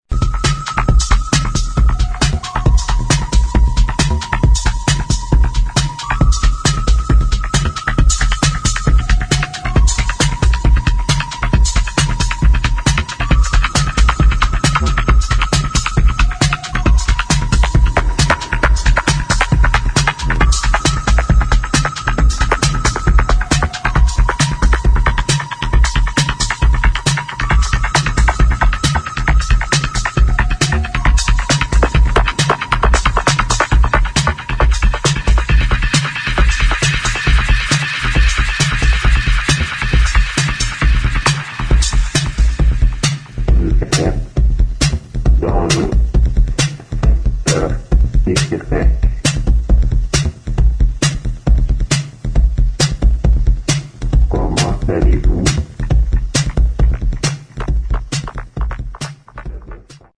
[ TECHNO / ELECTRO ]